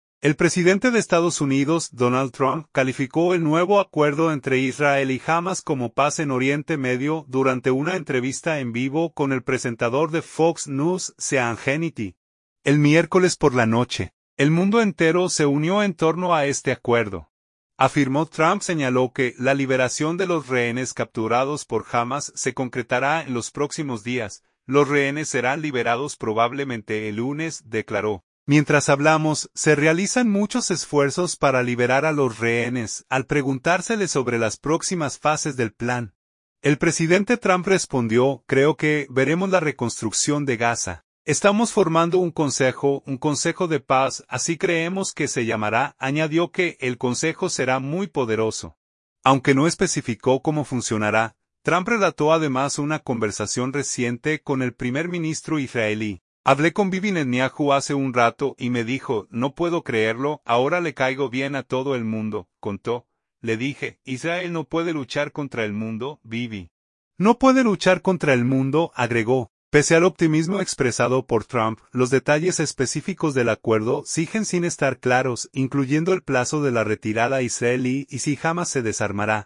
ESTADOS UNIDOS.- El presidente de Estados Unidos, Donald Trump, calificó el nuevo acuerdo entre Israel y Hamas como “paz en Oriente Medio”, durante una entrevista en vivo con el presentador de Fox News, Sean Hannity, el miércoles por la noche.